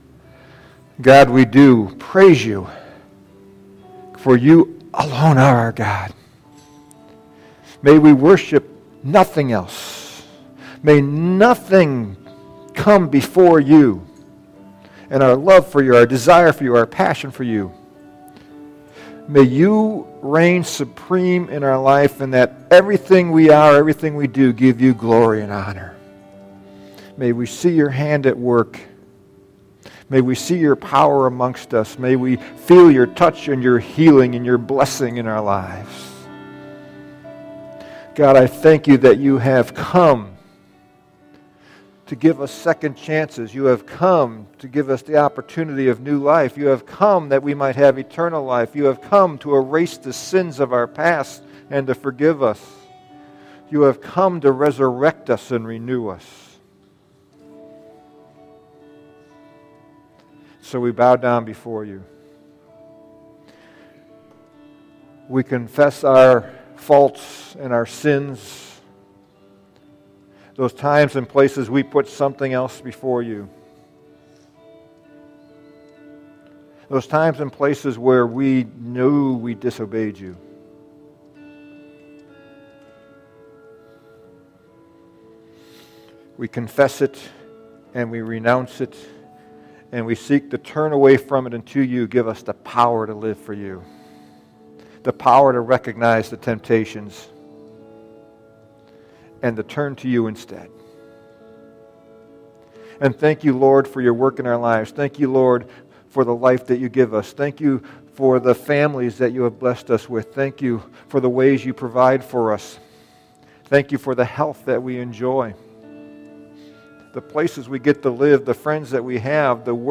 Serving – Edgewater Covenant Church